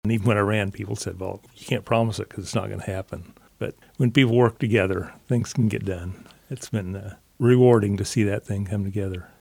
The new station will house a full-time ambulance and crew, providing faster response times to residents in northern Riley County. Commissioner Greg McKinley, campaigned in 2020 on the promise of a north county EMS station and has been vocal about the need for the service since his days on the Riley City Council.